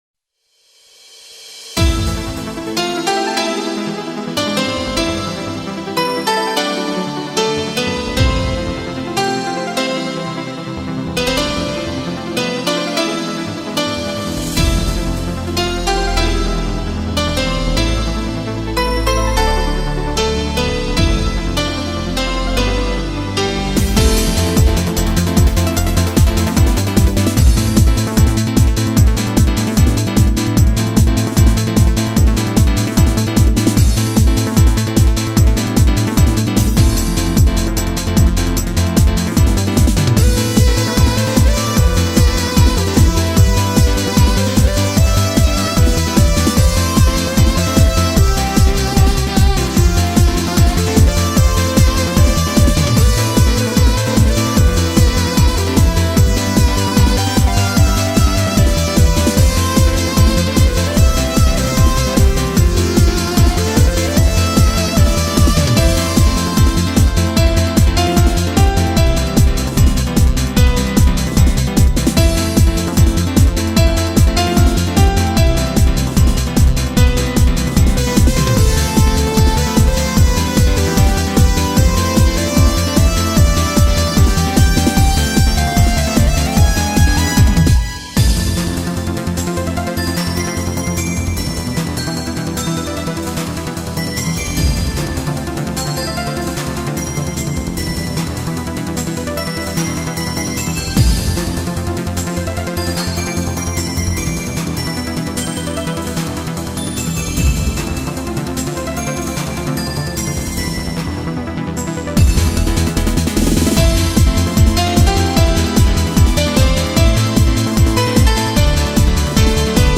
BPM150
Audio QualityMusic Cut
A remix of visual novel music?